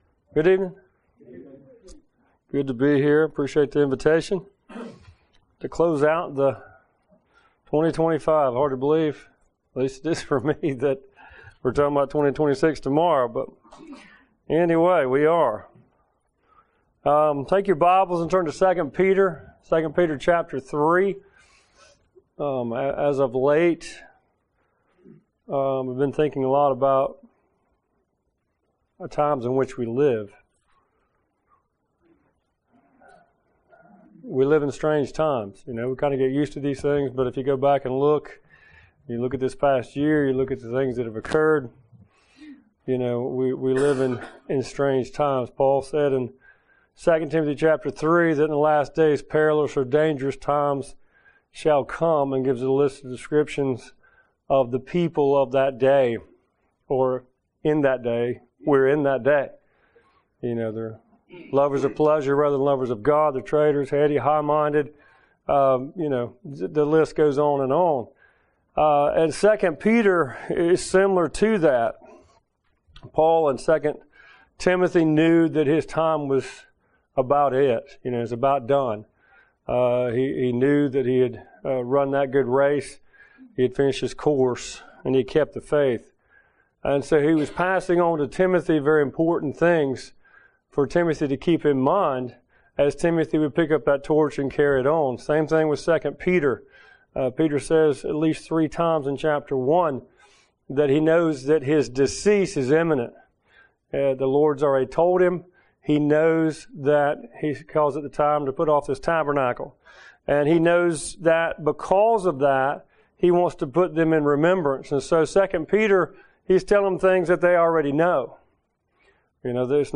Passage: 2 Peter 3:1-2, 8-18 Service Type: Wednesday Night Related « Divisions in the Church The Purpose of a Christian